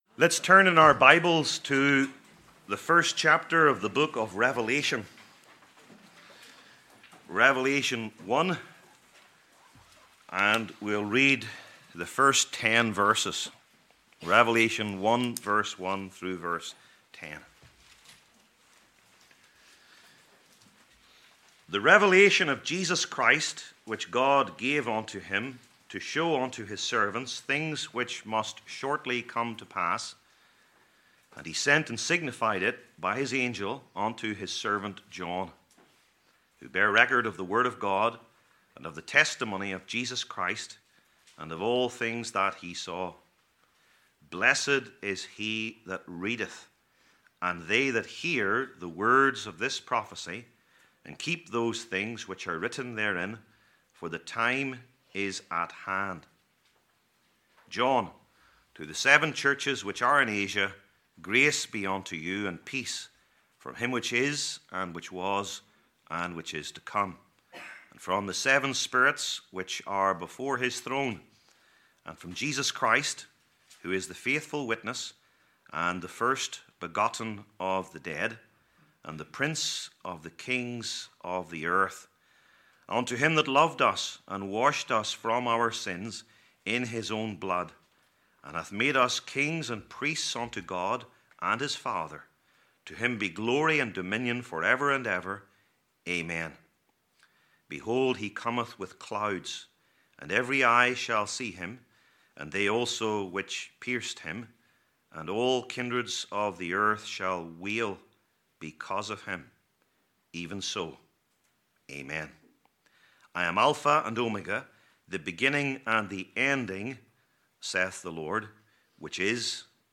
Service Type: Lectures/Debates/Interviews Topics: church history